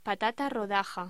Locución: Patata rodaja
voz